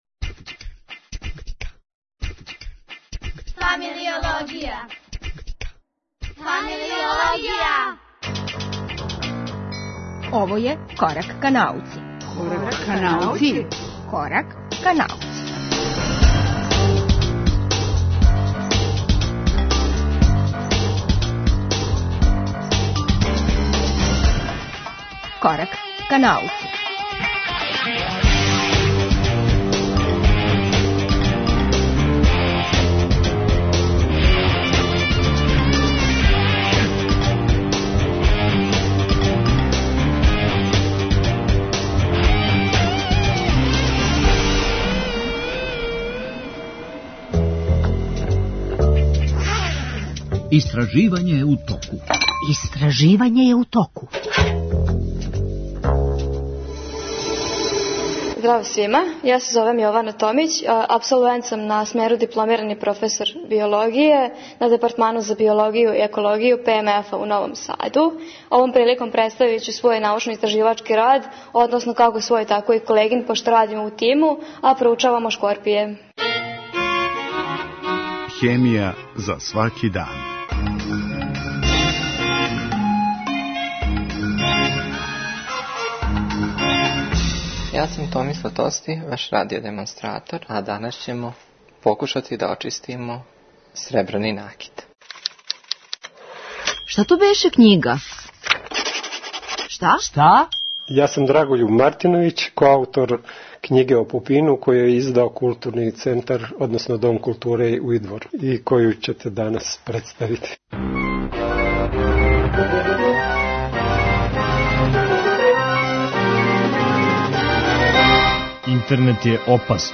У рубрици Акутуелно, идемо на Кавказ, међу учеснике завршне конференције Турнира градова, такмичења које окупља младе математичаре из разних земаља. Телефоном ће нам се јавити наши представници.